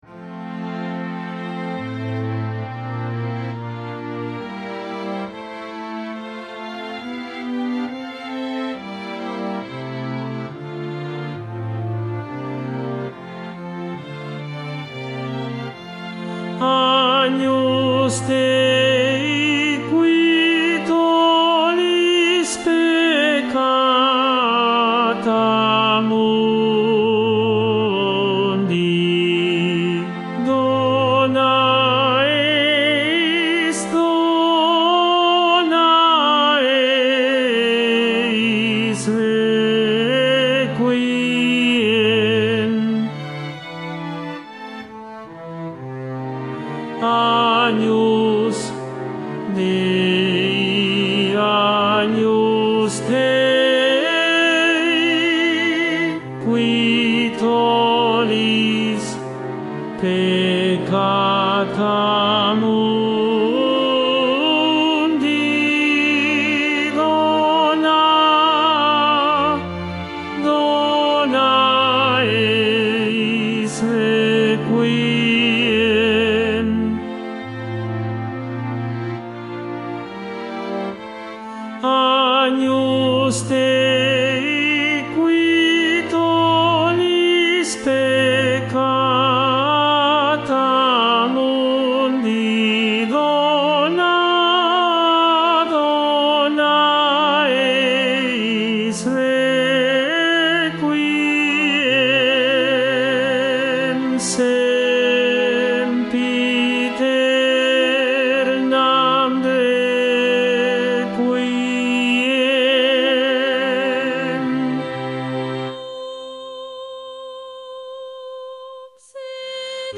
Tenor II